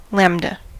lambda: Wikimedia Commons US English Pronunciations
En-us-lambda.WAV